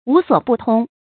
無所不通 注音： ㄨˊ ㄙㄨㄛˇ ㄅㄨˋ ㄊㄨㄙ 讀音讀法： 意思解釋： 通：知道。沒有什么不知道的。